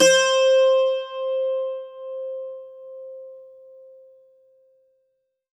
STRINGED INSTRUMENTS
52-str11-bouz-c4.wav